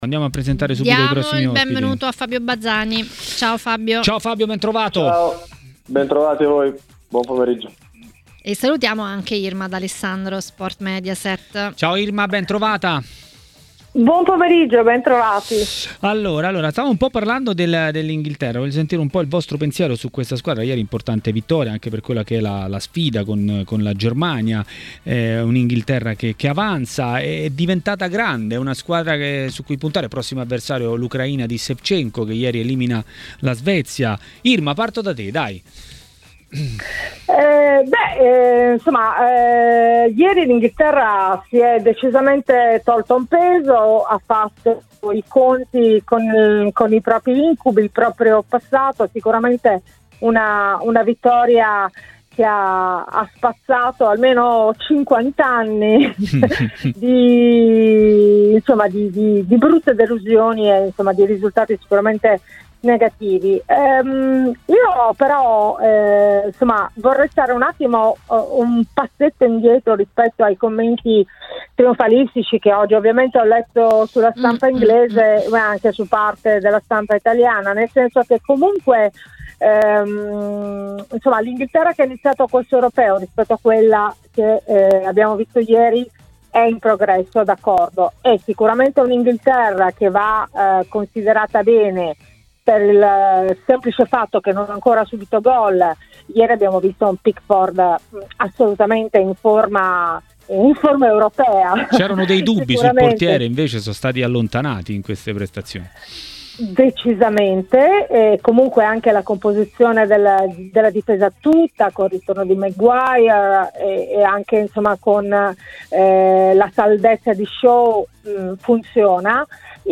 A parlare di Euro 2020 e non solo a Maracanà, nel pomeriggio di TMW Radio, è stato l'ex calciatore Fabio Bazzani.